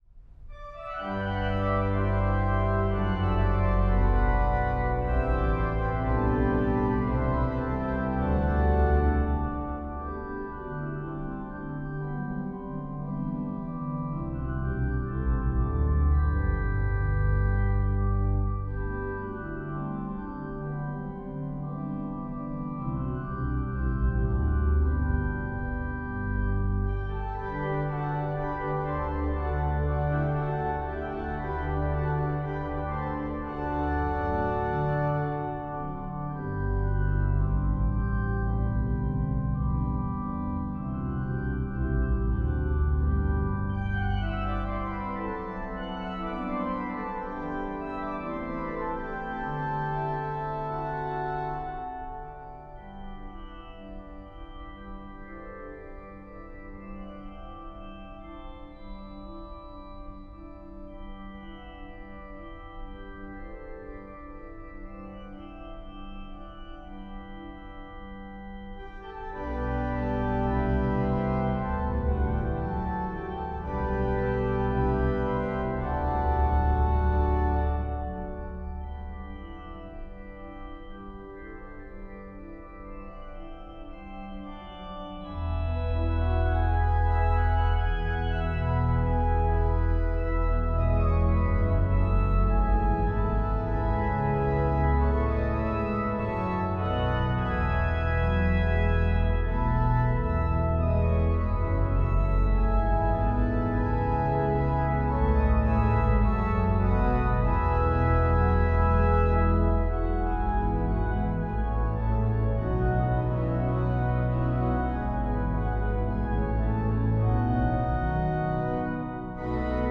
Voicing: Org 2-staff